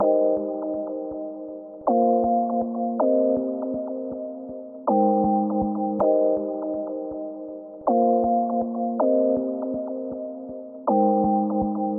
Tag: 160 bpm Trap Loops Synth Loops 2.02 MB wav Key : Unknown